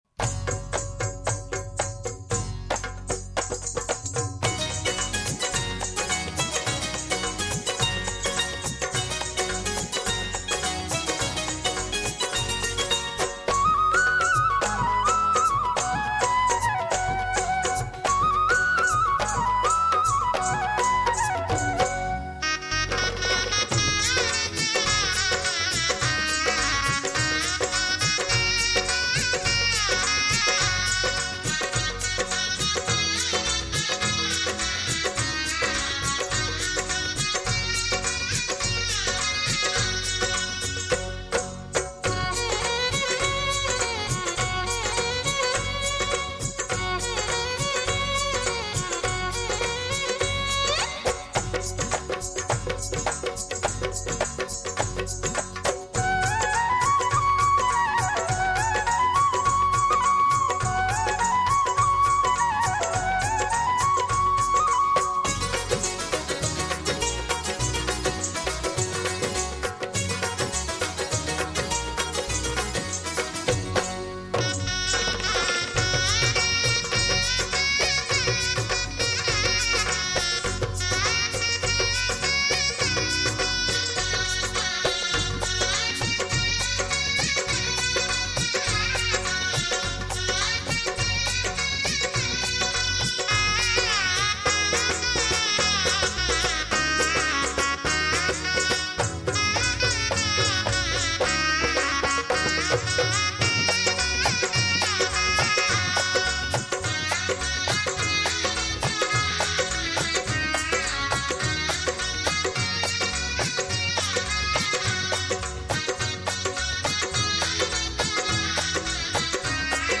Rajasthani Vivah Geet Shehnai